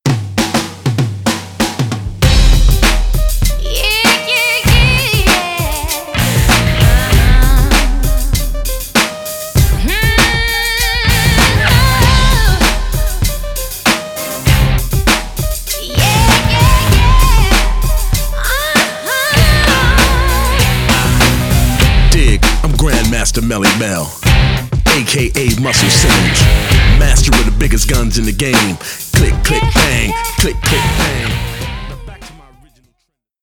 live drums